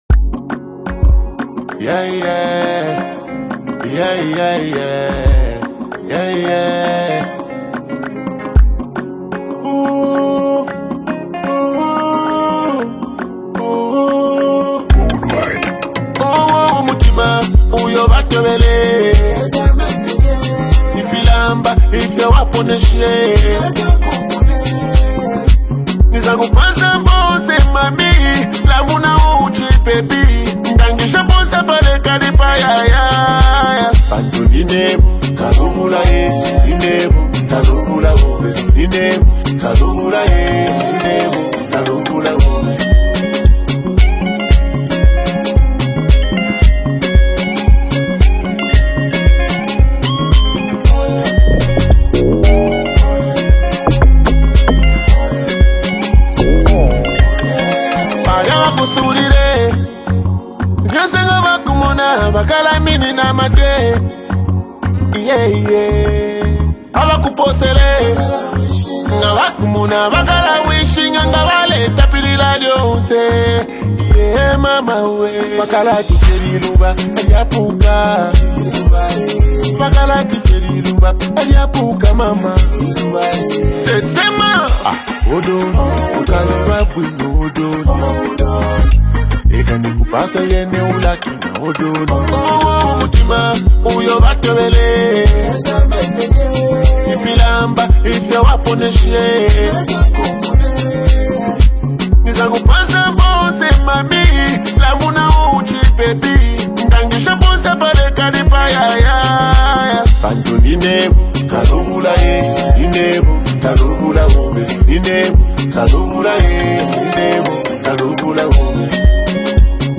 a lively and energetic song